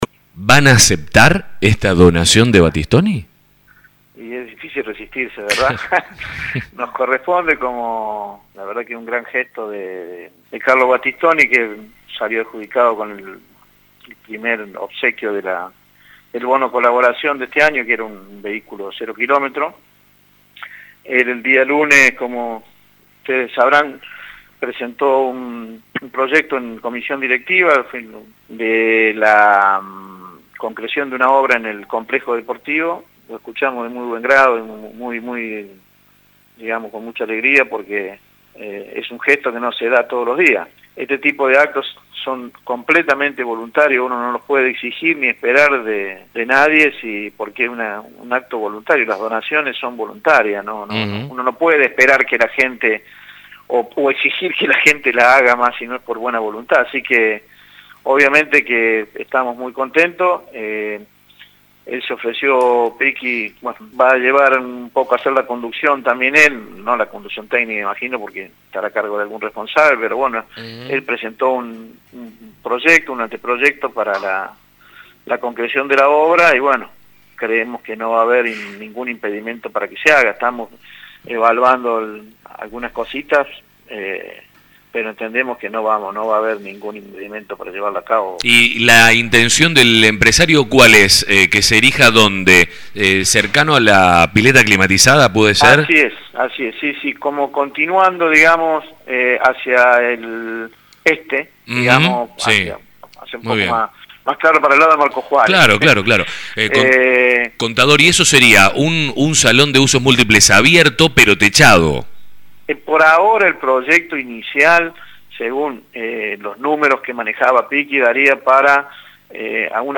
En conversaciones con «La Mañana»